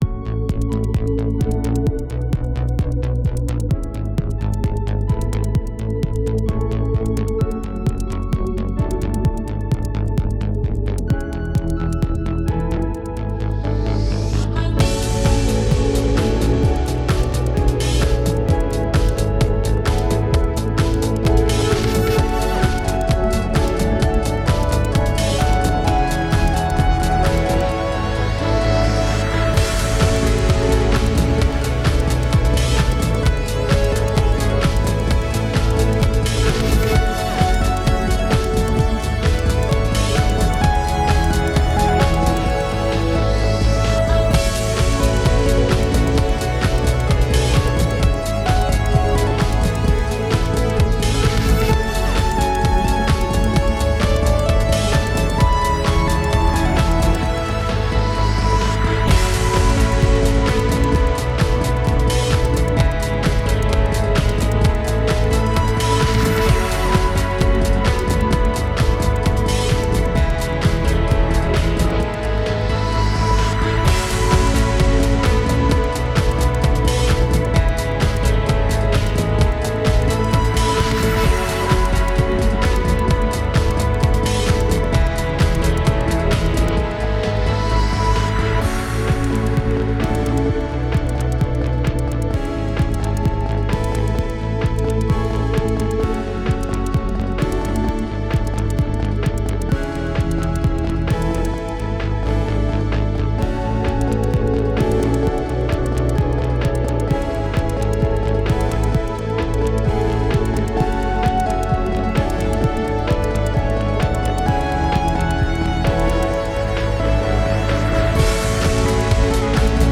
This mix is special